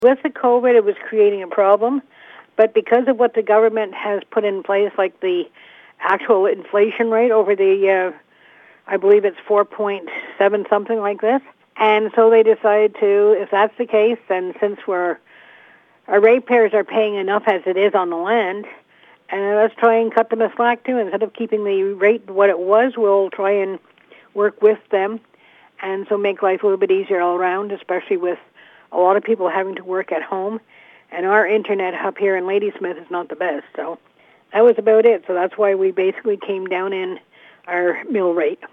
Thorne Mayor Karen Kelly discussed the 2022 budget with CHIP 101.9. Photo courtesy of MRC Pontiac.